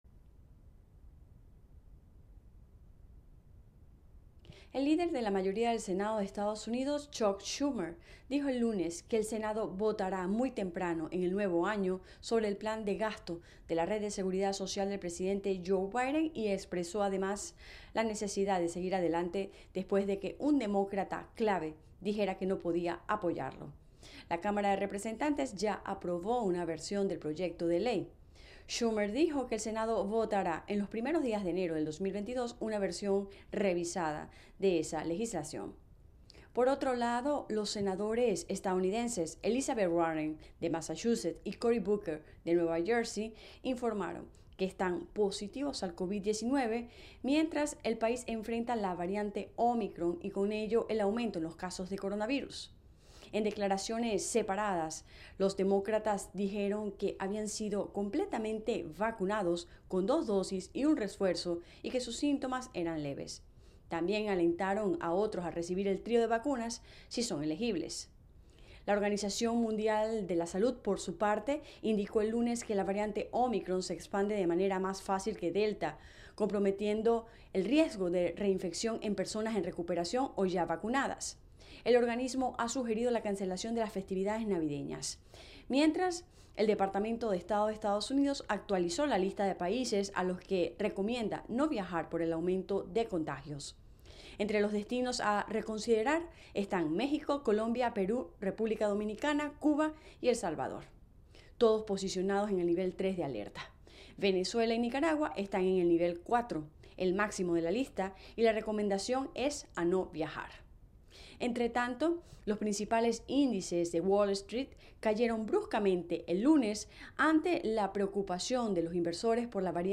La agenda del día [Radio]